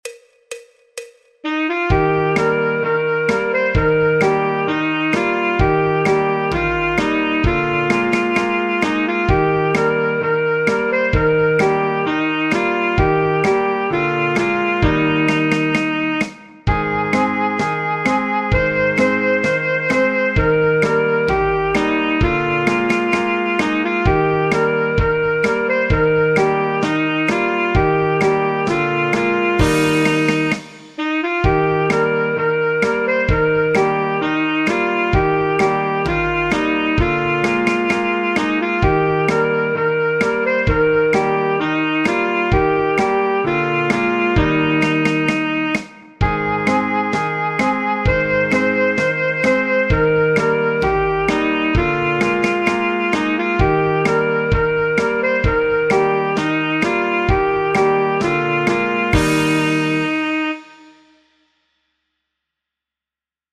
El MIDI tiene la base instrumental de acompañamiento.
Saxofón Alto / Saxo Barítono
Folk, Popular/Tradicional